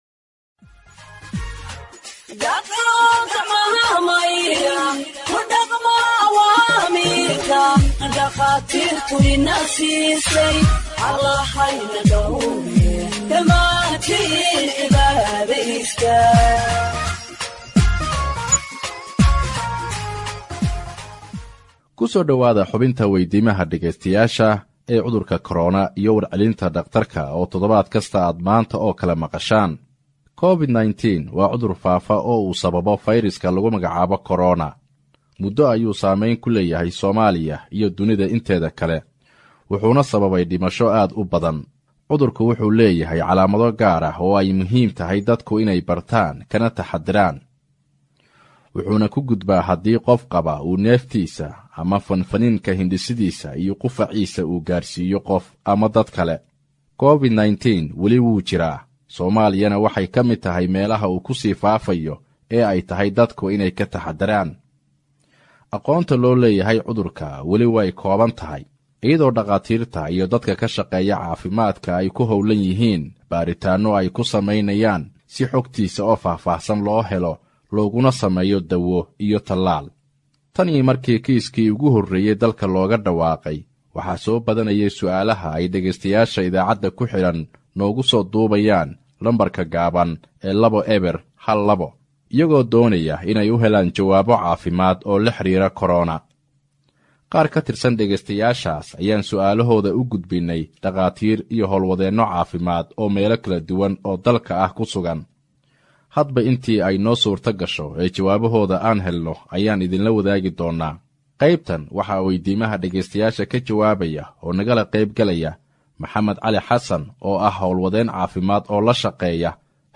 HEALTH EXPERT ANSWERS LISTENERS’ QUESTIONS ON COVID 19 (64)
Radio Ergo provides Somali humanitarian news gathered from its correspondents across the country for radio broadcast and website publication.